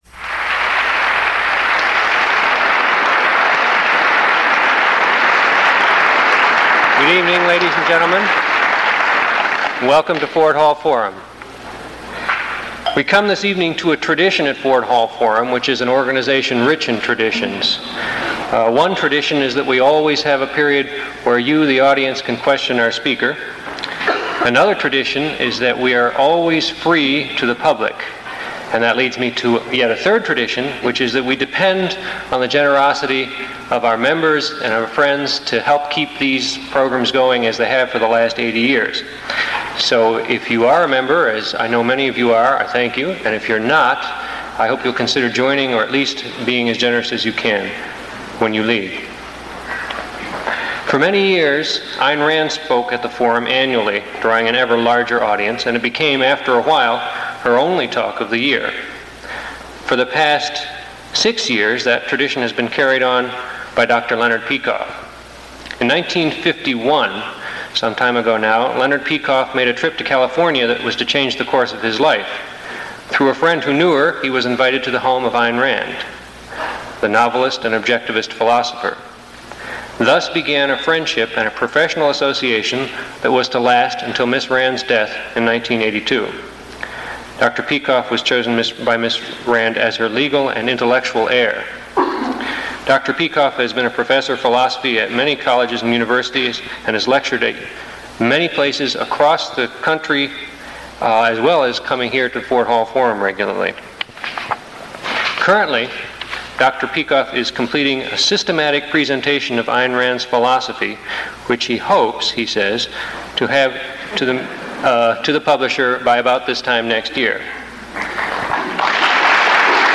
In this path-breaking lecture, Dr. Leonard Peikoff contrasts the modern complexity worship with a life lived according to principles.
Below is a list of questions from the audience taken from this lecture, along with (approximate) time stamps.